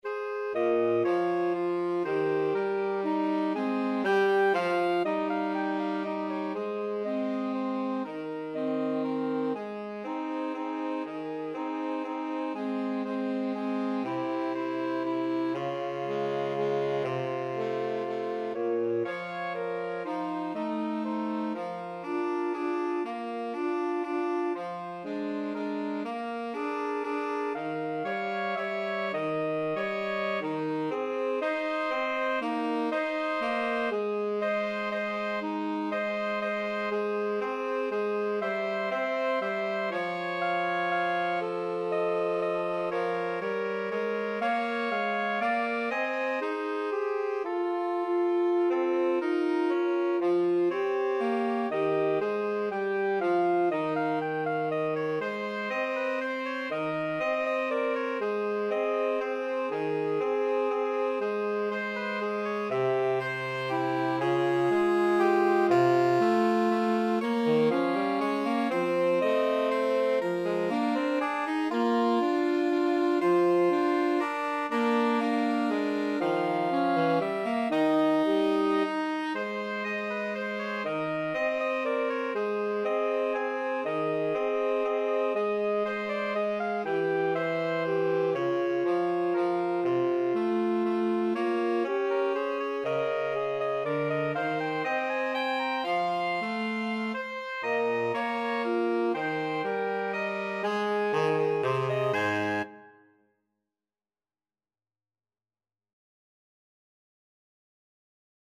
Soprano SaxophoneAlto SaxophoneTenor Saxophone
3/4 (View more 3/4 Music)
= 120 Tempo di Valse = c. 120